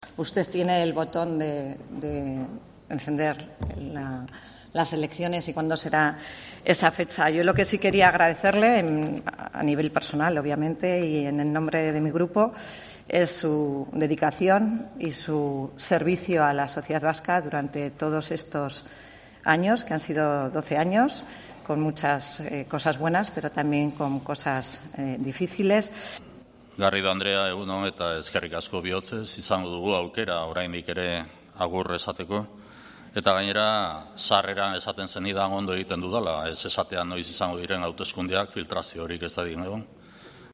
AUDIO: Cruce de despedidas entre la portavoz del PP, Laura Garrido, y Urkullu, en su último pleno